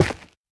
Minecraft Version Minecraft Version snapshot Latest Release | Latest Snapshot snapshot / assets / minecraft / sounds / block / basalt / break3.ogg Compare With Compare With Latest Release | Latest Snapshot
break3.ogg